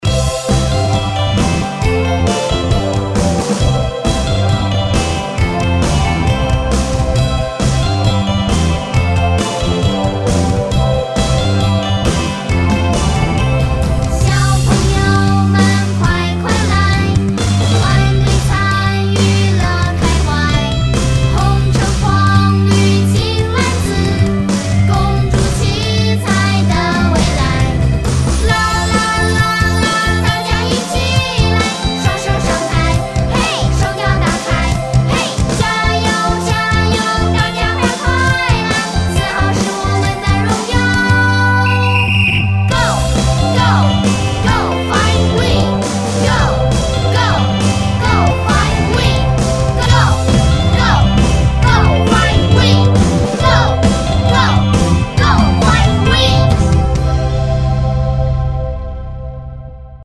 注：线上教学背景音乐，如需要，请点击附件自行下载。（仅支持ＰＣ版本下载）